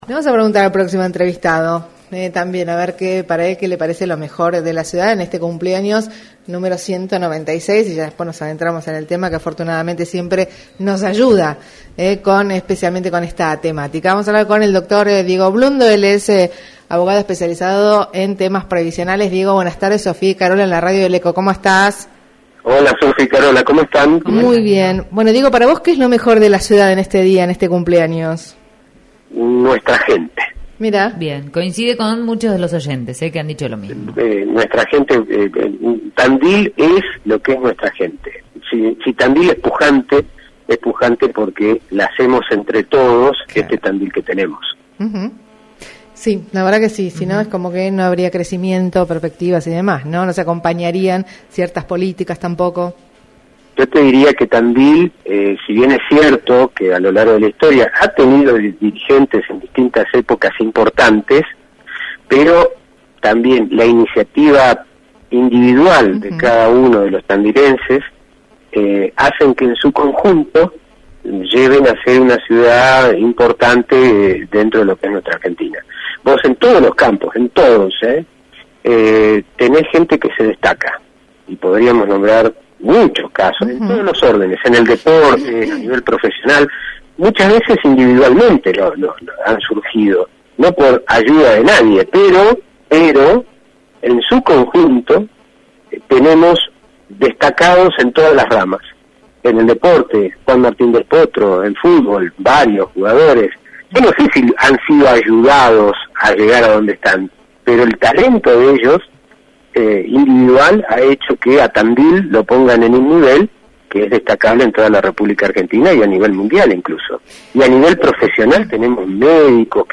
Tandil FM Entrevista